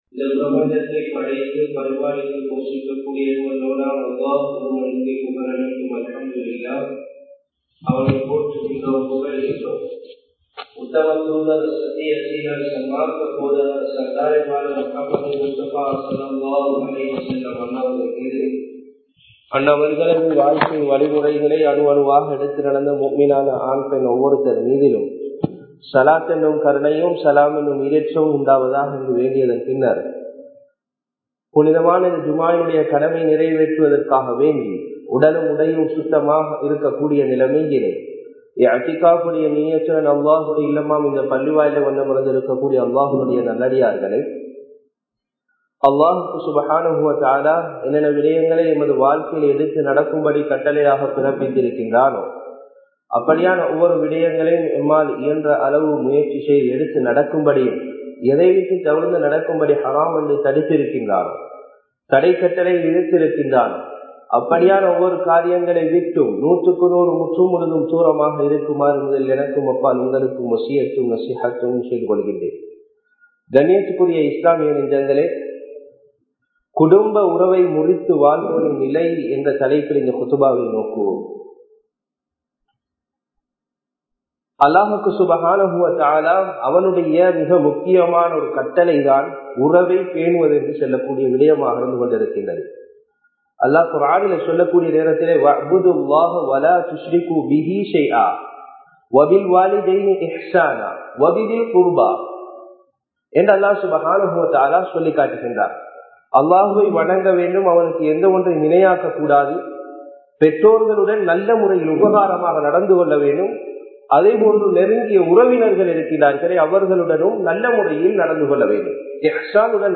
குடும்ப உறவை முறிக்காதீர்கள் (Dont break family bonds) | Audio Bayans | All Ceylon Muslim Youth Community | Addalaichenai